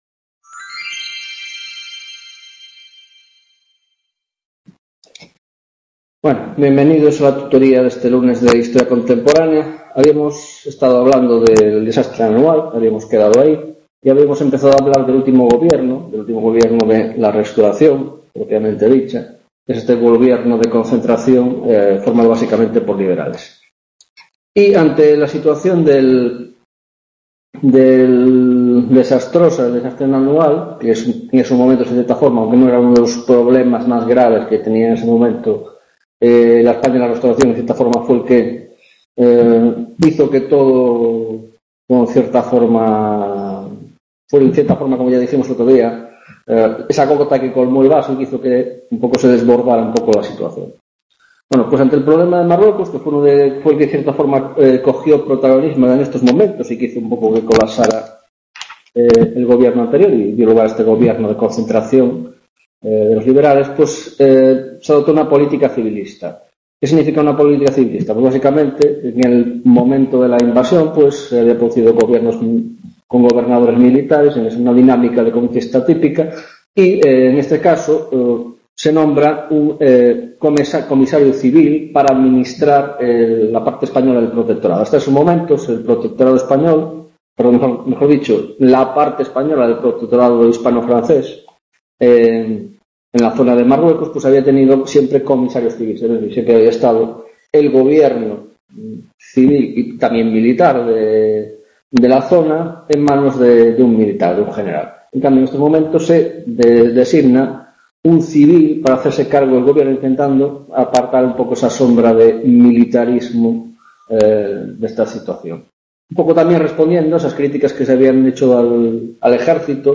19ª tutoria de Historia Contemporánea - Dictadura de Primo de Rivera, 1ª parte